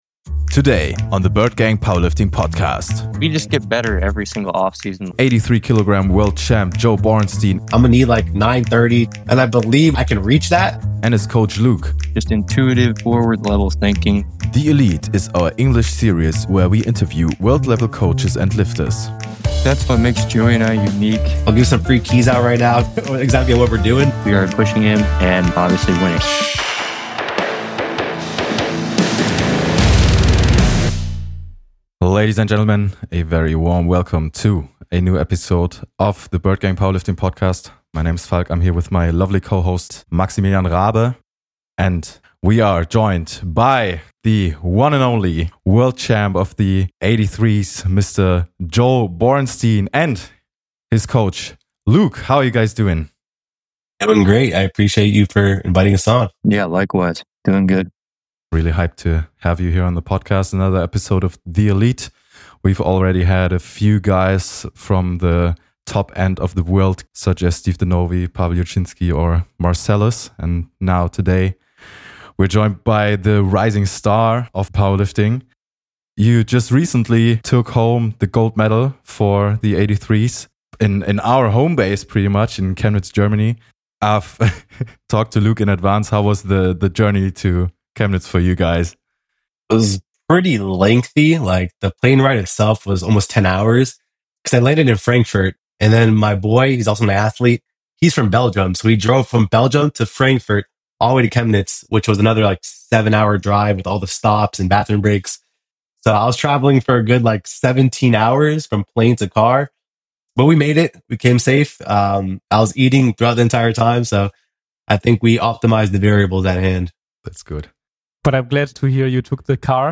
THE ELITE is our english series where we interview world level coaches and lifters.